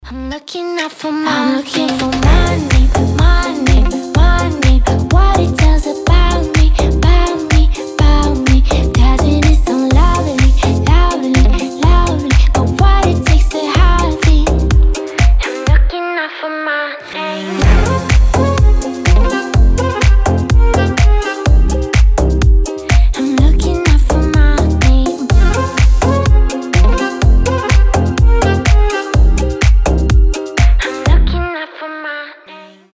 • Качество: 320, Stereo
милые
красивый женский голос